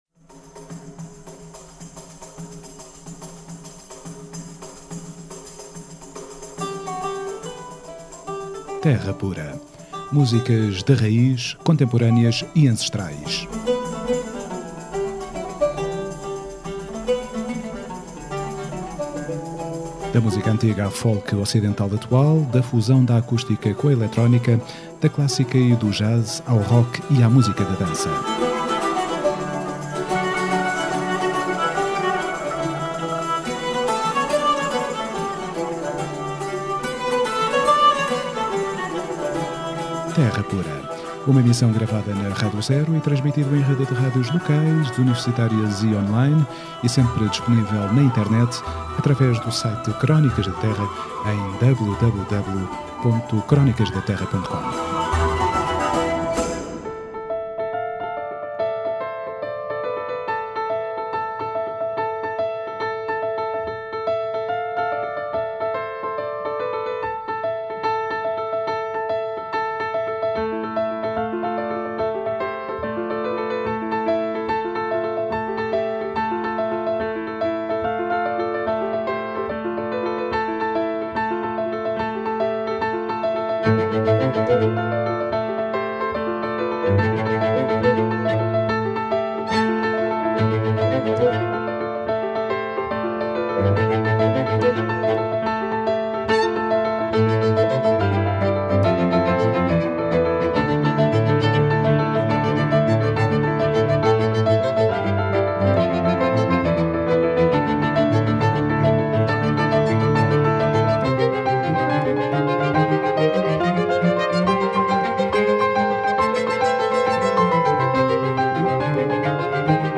Terra Pura 19NOV14: Entrevista Caixa de Pandora